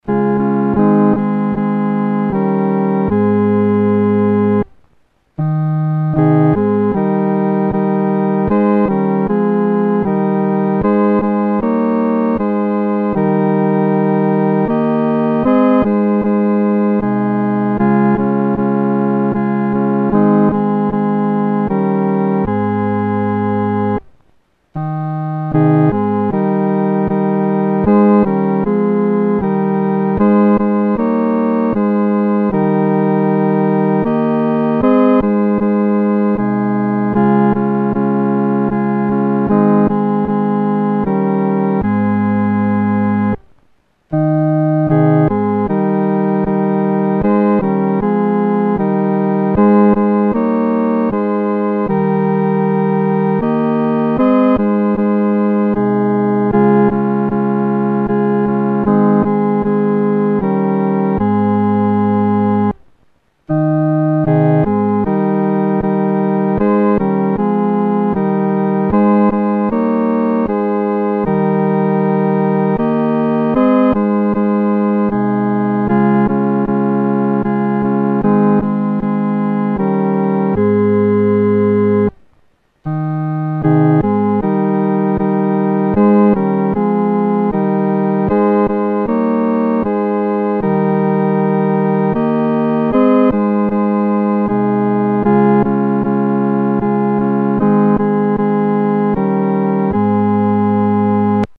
独奏（第三声）
万口欢唱-独奏（第三声）.mp3